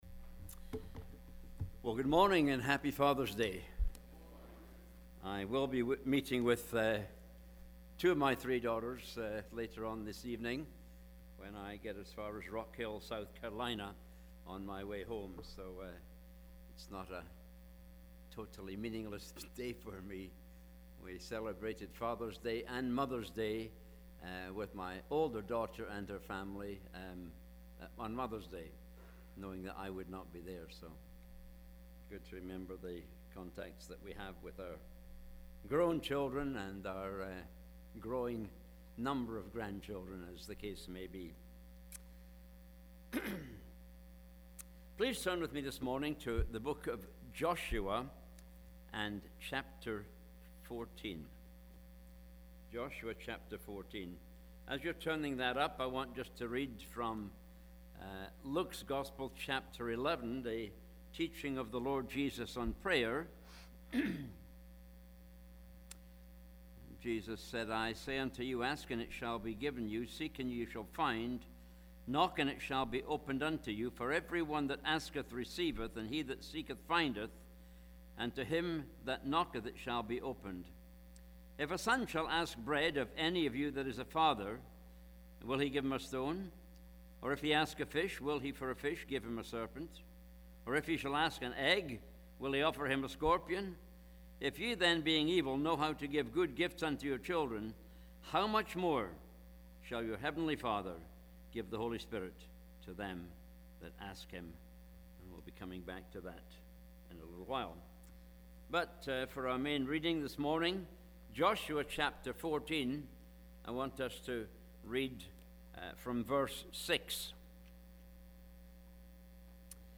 Weekly Sermons - Martinez Bible Chapel - Page 3